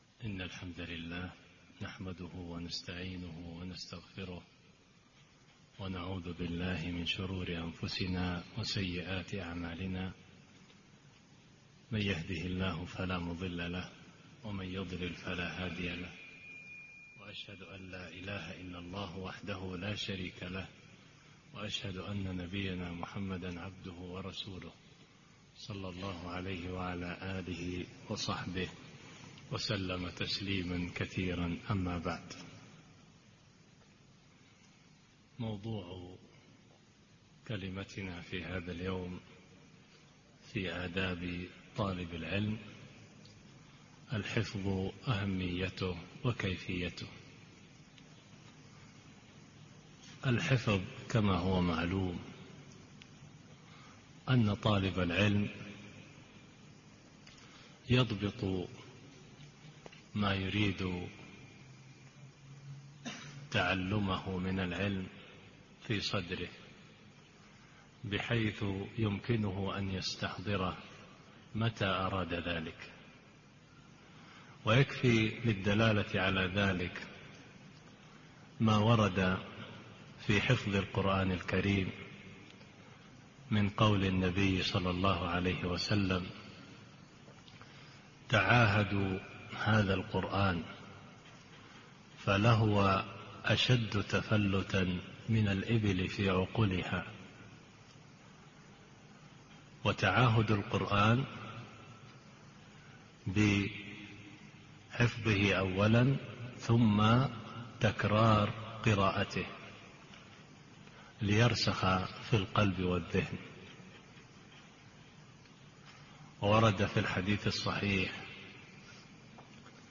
محاضرة - الحفظ أهميته وكيفيته